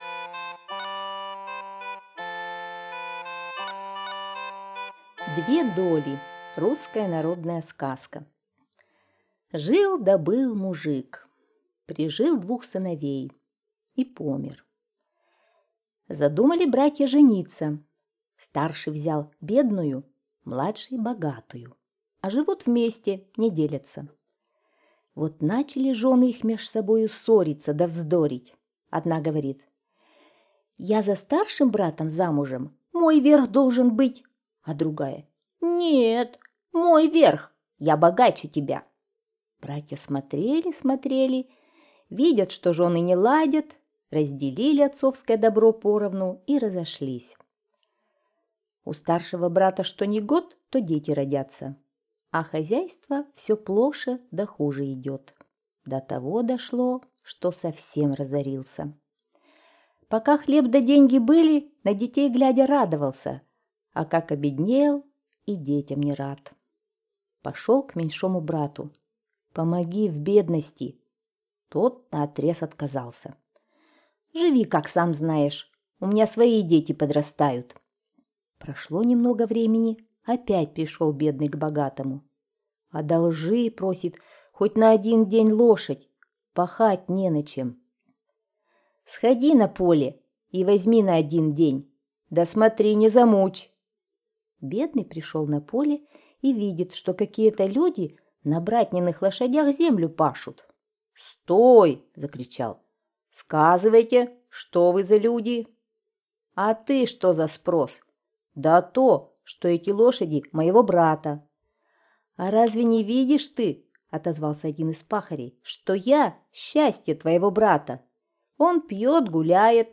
Две доли – русская народная аудиосказка